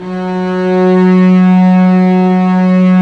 Index of /90_sSampleCDs/Roland - String Master Series/STR_Vcs Bow FX/STR_Vcs Sordino